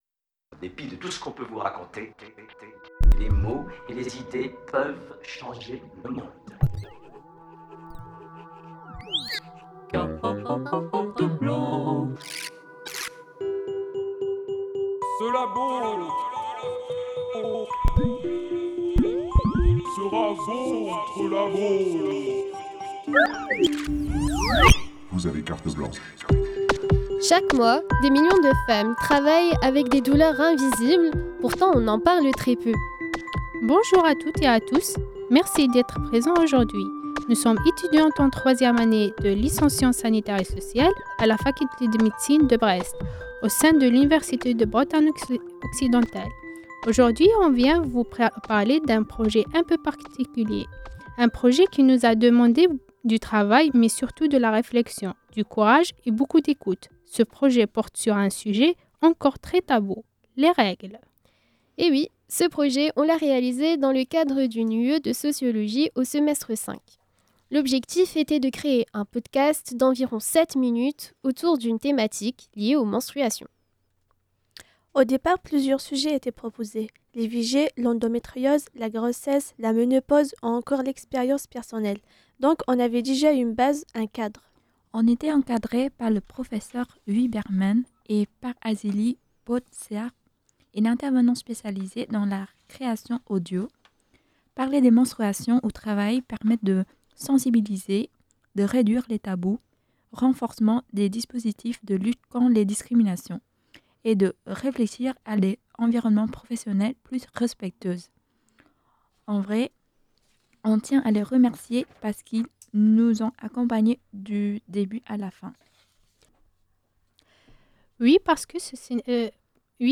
Par les étudiantes en licence sciences sanitaires et sociales à la faculté de médecine de Brestecine de Brest
C’est aussi l’occasion pour elles de s’initier au direct radio !